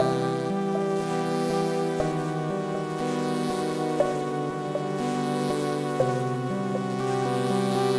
标签： 60 bpm Ambient Loops Synth Loops 1.35 MB wav Key : Unknown
声道立体声